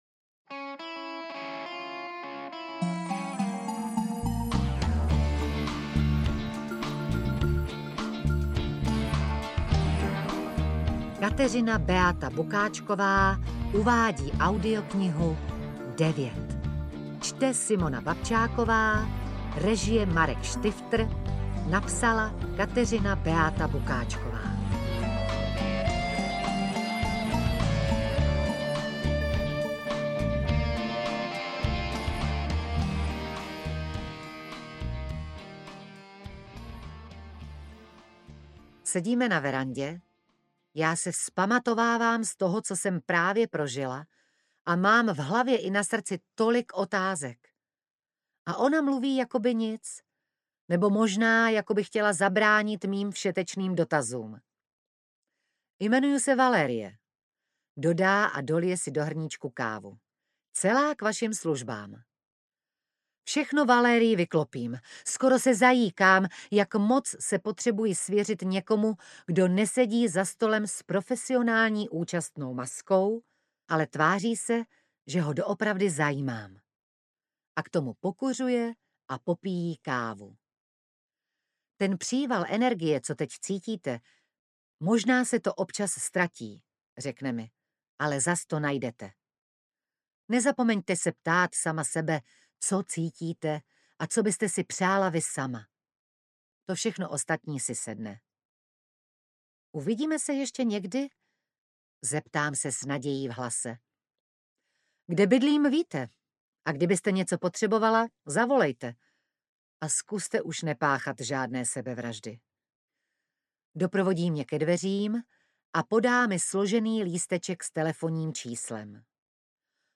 Devět audiokniha
Ukázka z knihy
• InterpretSimona Babčáková